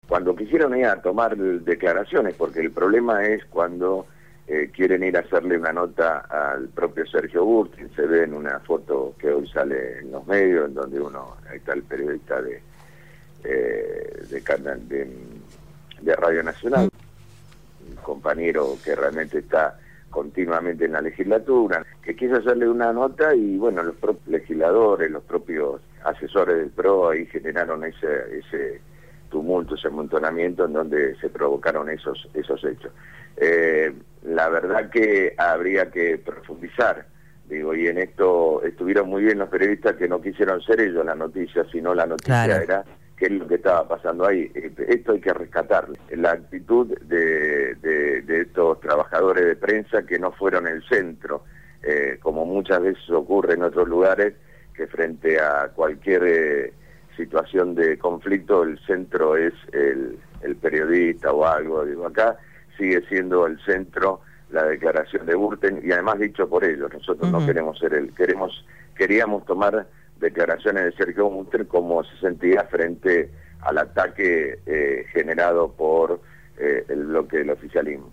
entrevistaron al Legislador del EPV Francisco «Tito» Nenna